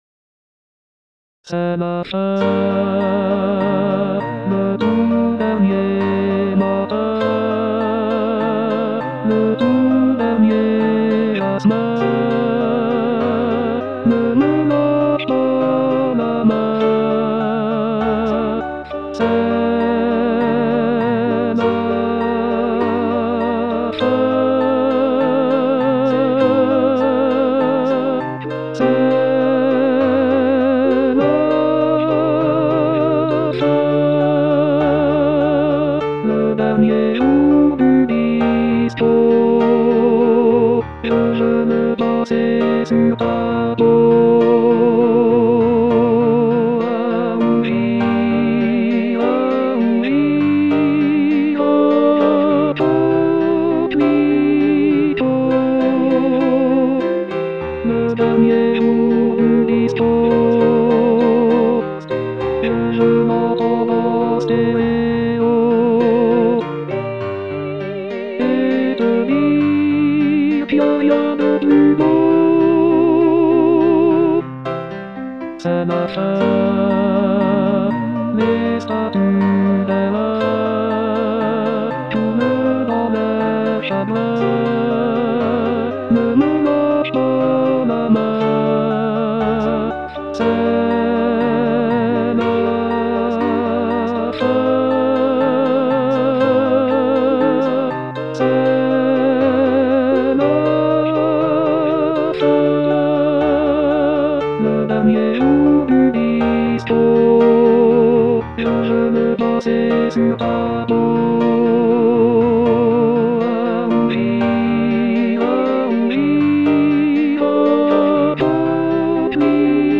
voix chantée IA